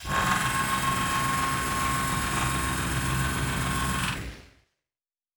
pgs/Assets/Audio/Sci-Fi Sounds/Mechanical/Servo Big 5_2.wav at master
Servo Big 5_2.wav